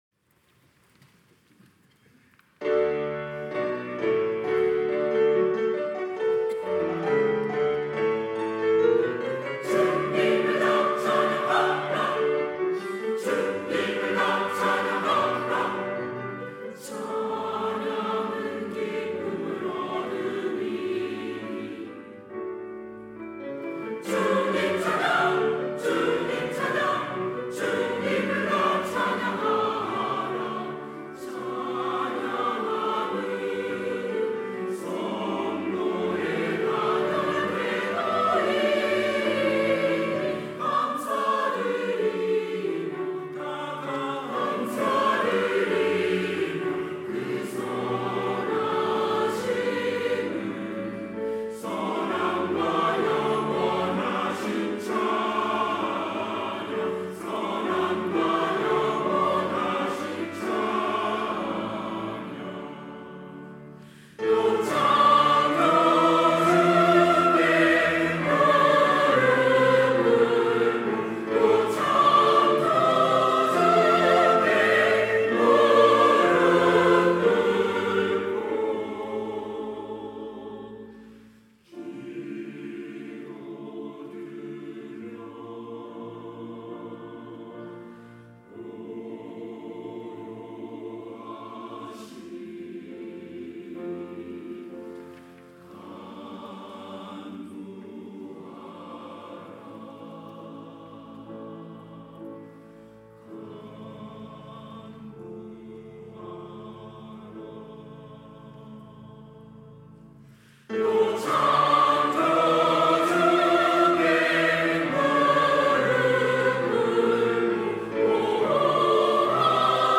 찬양대 시온